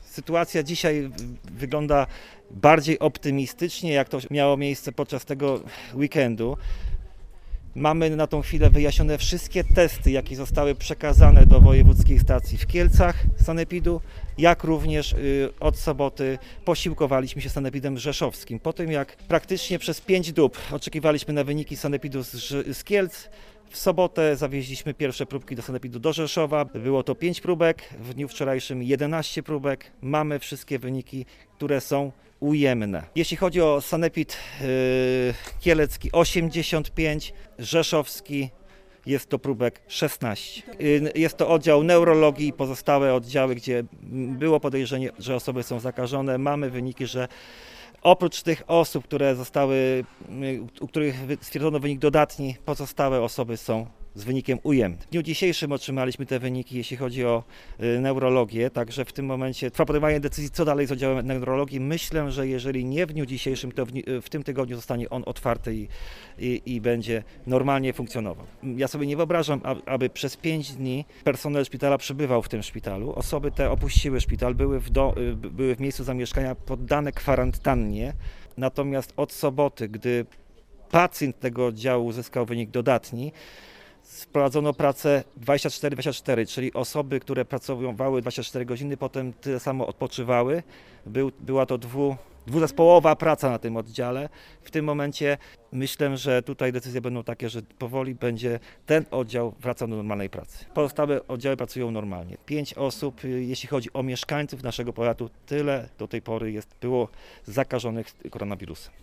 ’- Są najnowsze wyniki badań w kierunku zakażenia koronawirusem szpitala w Sandomierzu – mówi starosta sandomierski Marcin Piwnik.